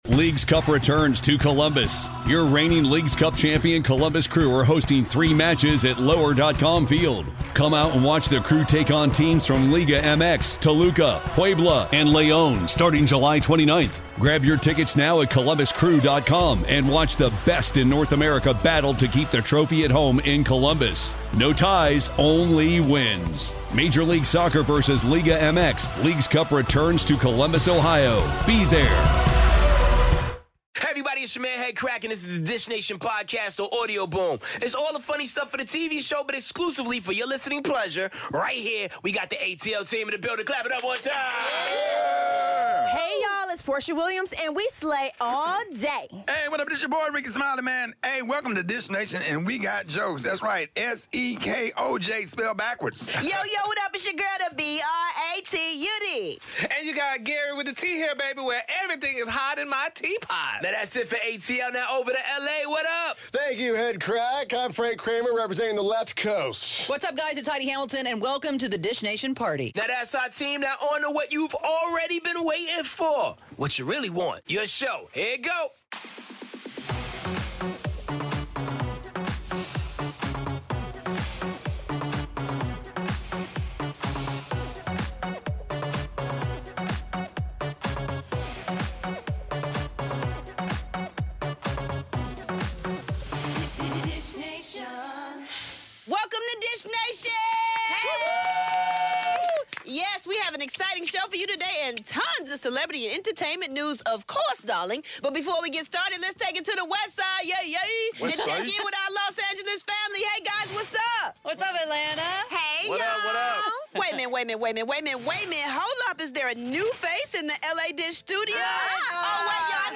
Sherri Shepherd is in studio givin' us the exclusive dish on 'The Masked Singer,'